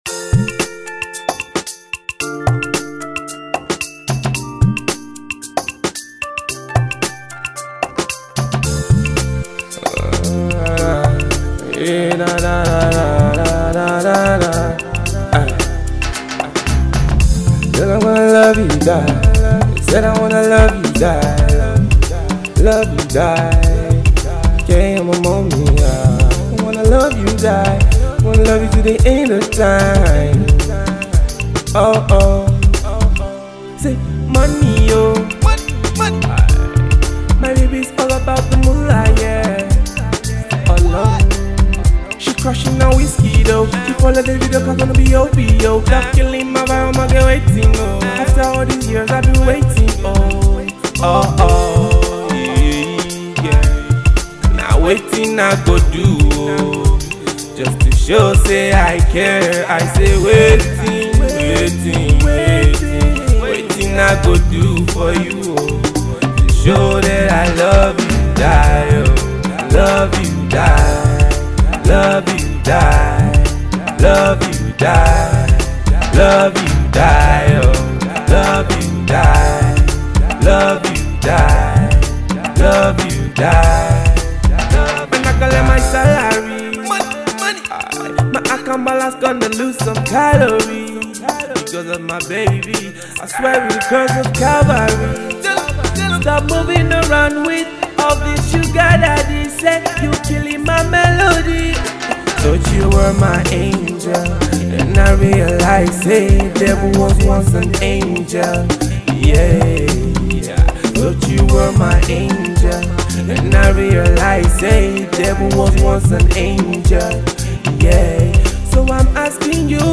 The smooth romantic number